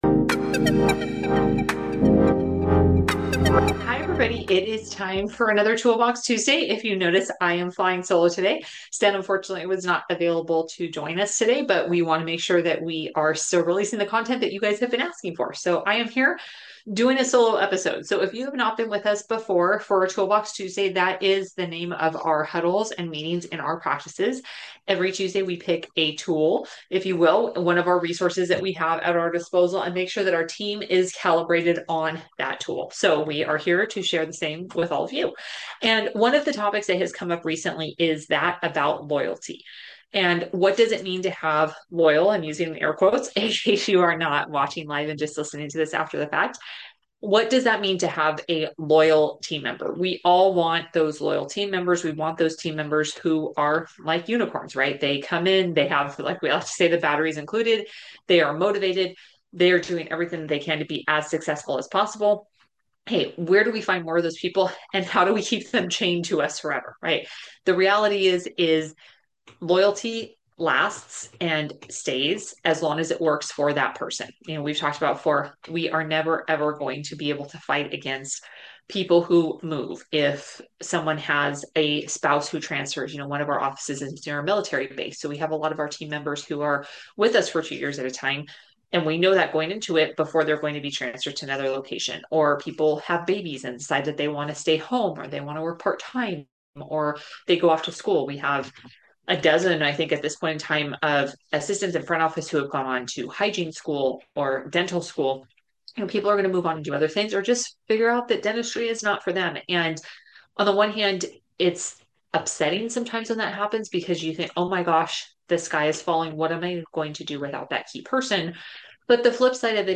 Is your dental practice overly reliant on one long-tenured team member? In this solo episode